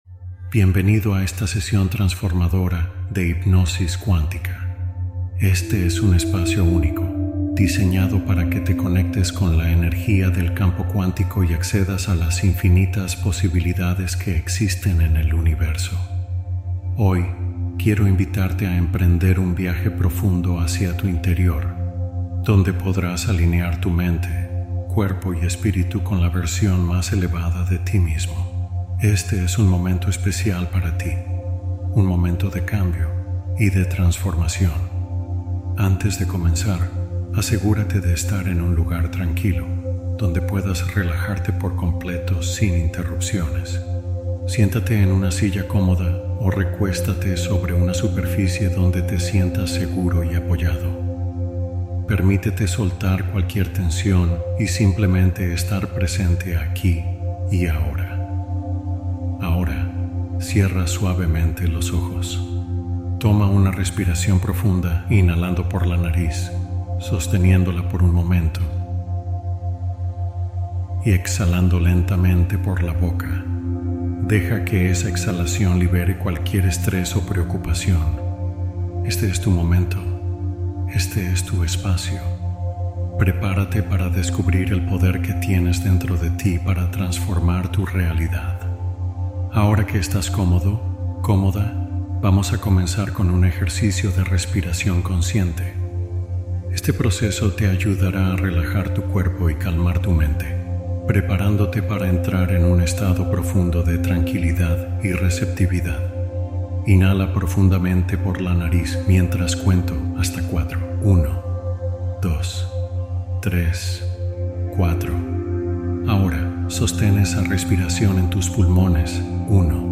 Meditación de Hipnosis Cuántica Para Manifestar en una Noche